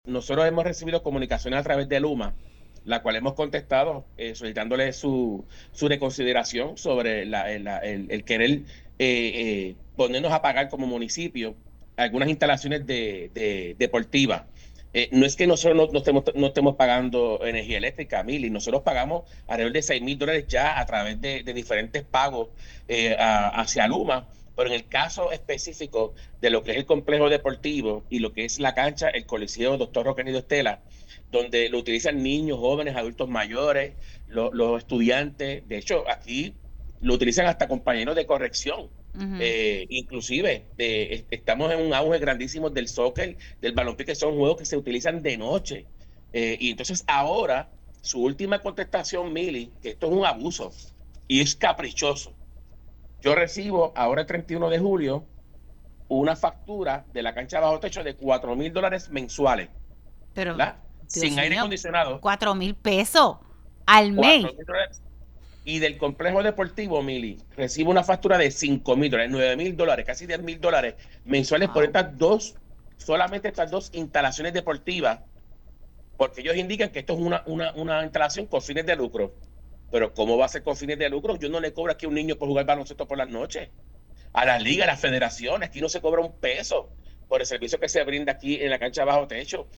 El alcalde de Guayama, Obrain Vázquez, abogó en este medio a favor de una reconsideración a la compañía energética LUMA Energy a pesar de acuerdo sobre contribución en lugar de impuestos (CELI).